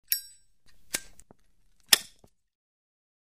На этой странице собраны разнообразные звуки зажигалок: от металлических щелчков до гула пламени.
Звук чирканья зажигалки